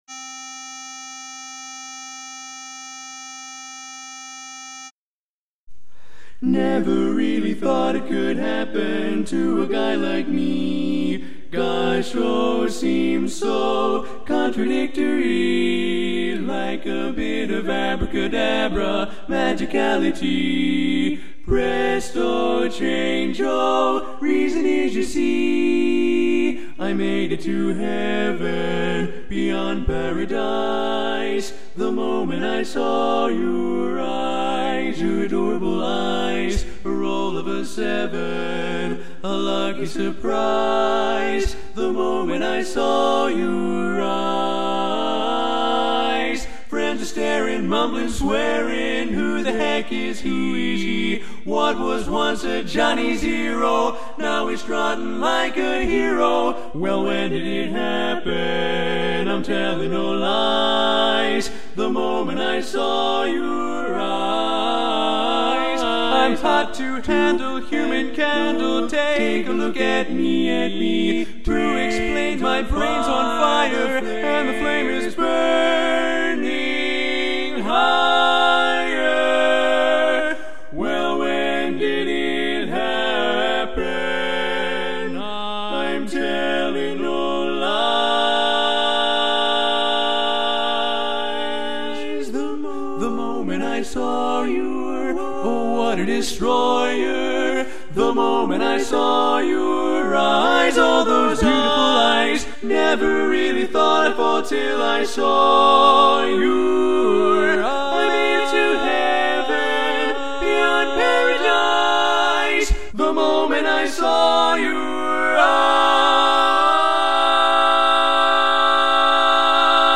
Kanawha Kordsmen (chorus)
Up-tempo
C Major